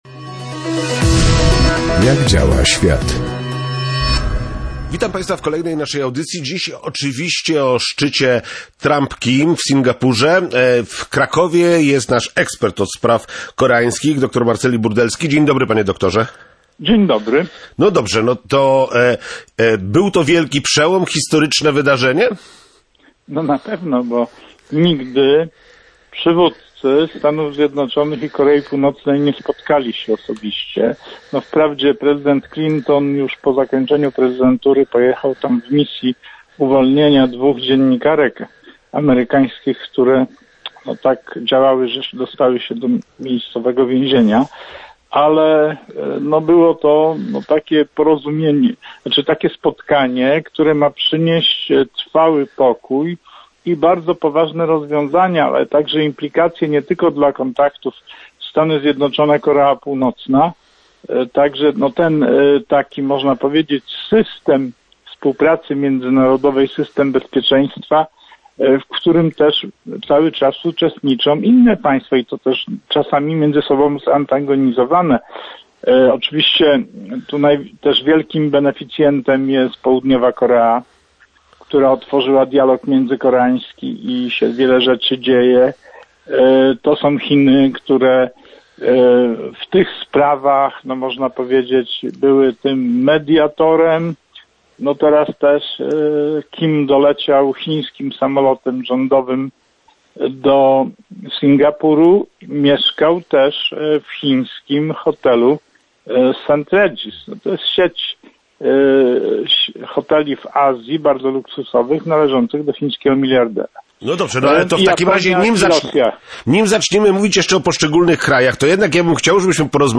komentował w programie Jak działa świat ekspert od stosunków międzynarodowych